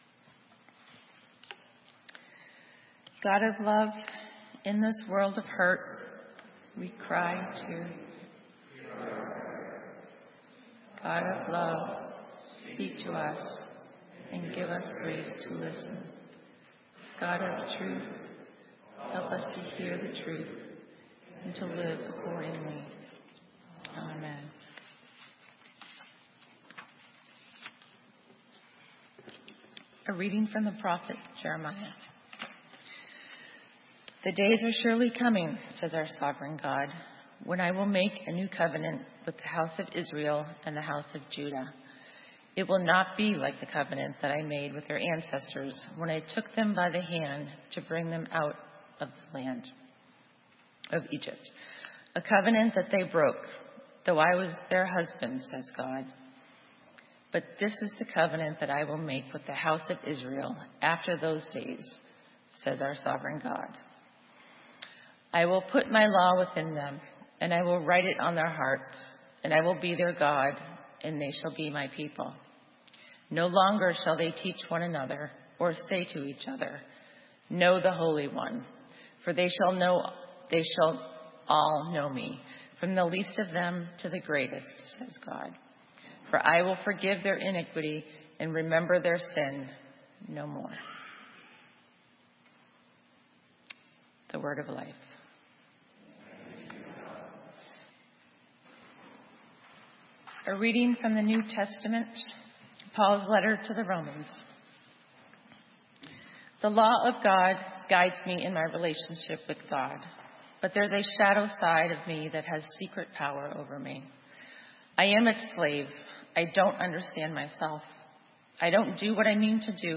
Sermon:Pleading widow - St. Matthews United Methodist Church